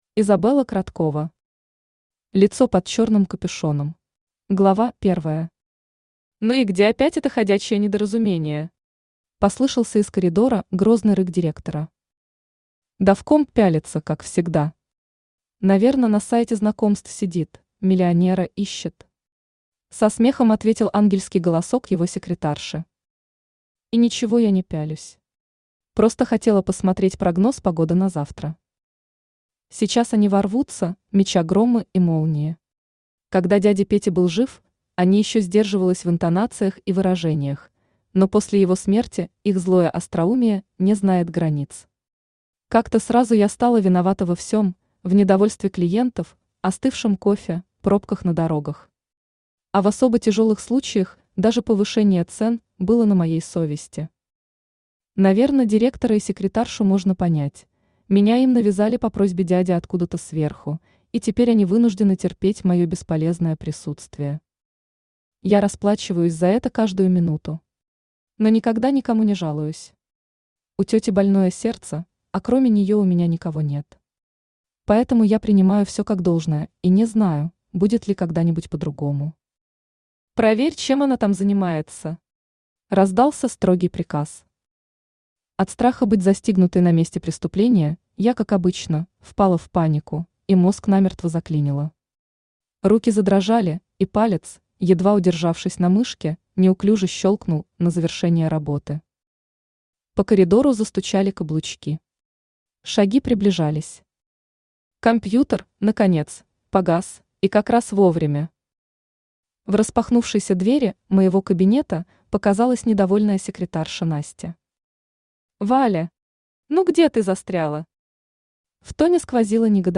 Аудиокнига Лицо под чёрным капюшоном | Библиотека аудиокниг
Aудиокнига Лицо под чёрным капюшоном Автор Изабелла Кроткова Читает аудиокнигу Авточтец ЛитРес.